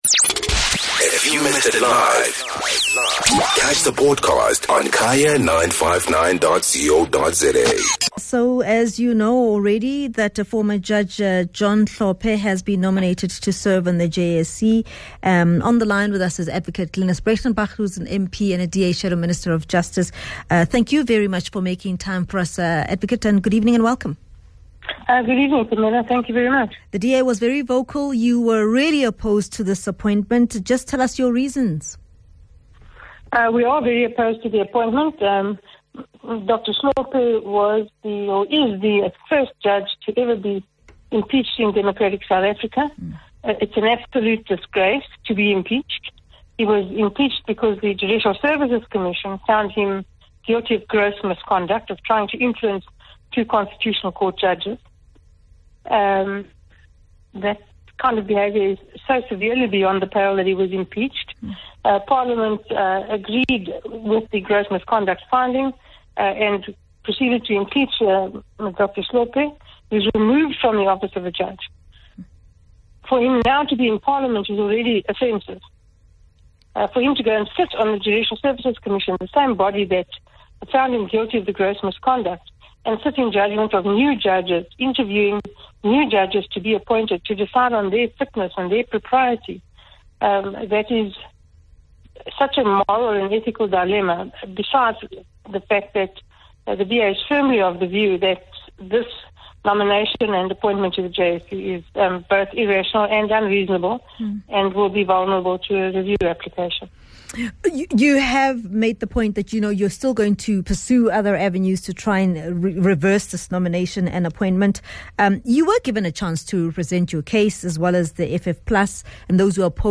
Guest: Glynnis Breytenbach -DA MP